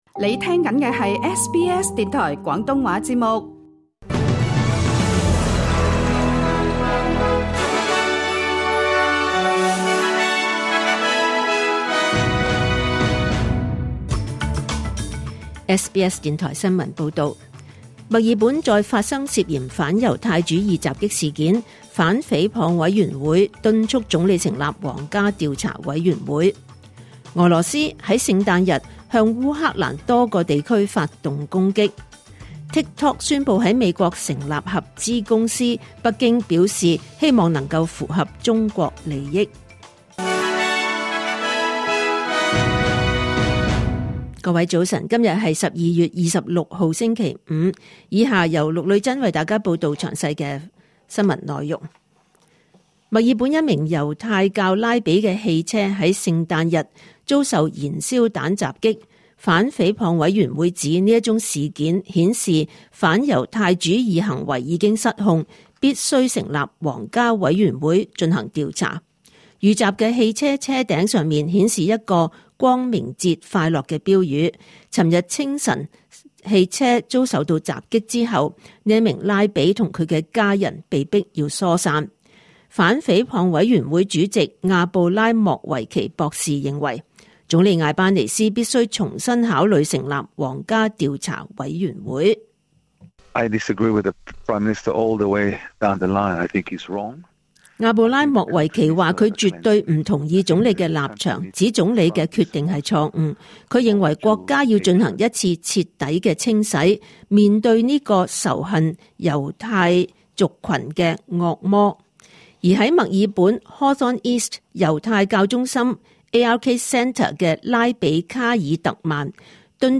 2025年12月26日SBS廣東話節目九點半新聞報道。